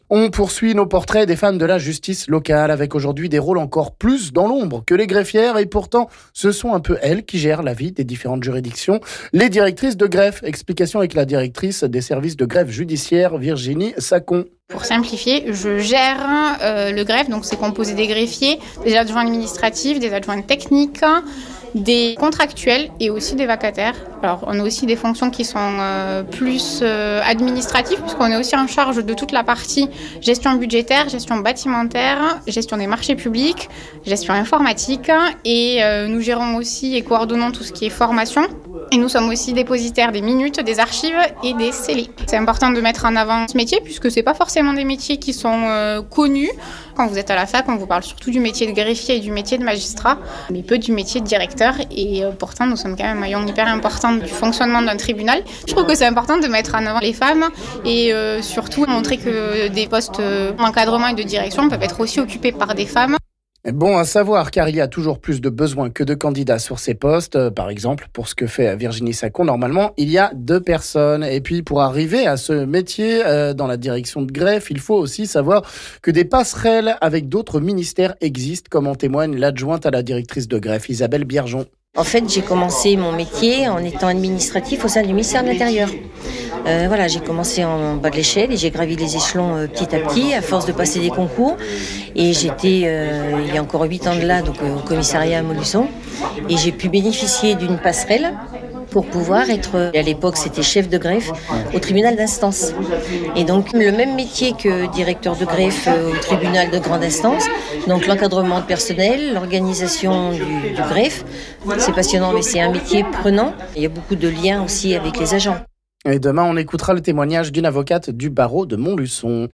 Témoignages de directrices de greffe au tribunal de Montluçon